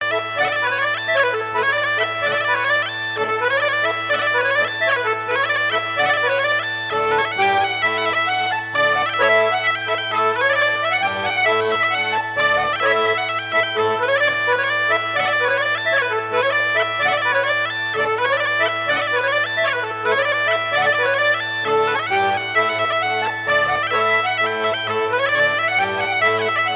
Marais Breton Vendéen
danse : branle : courante, maraîchine